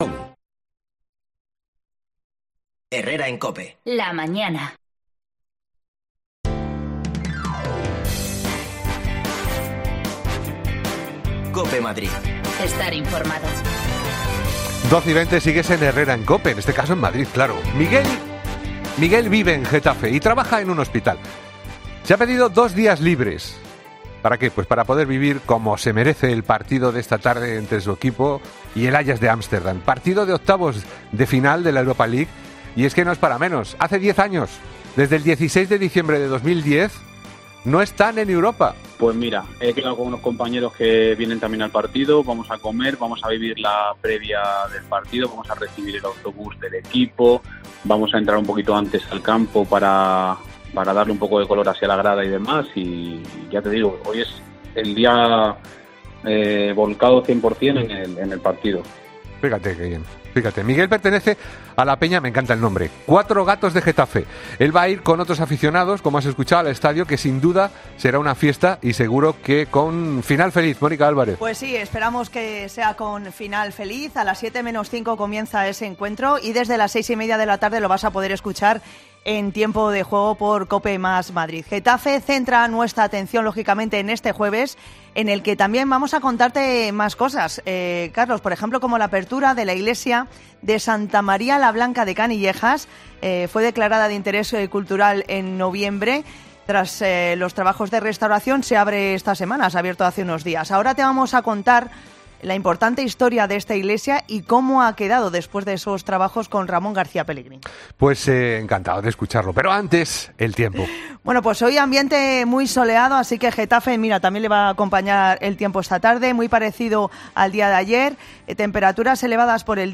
Escucha ya las desconexiones locales de Madrid de Herrera en COPE en Madrid y Mediodía COPE en Madrid .
Las desconexiones locales de Madrid son espacios de 10 minutos de duración que se emiten en COPE , de lunes a viernes.